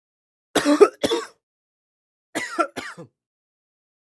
Wheezy Cough
Wheezy Cough is a free sfx sound effect available for download in MP3 format.
Wheezy Cough.mp3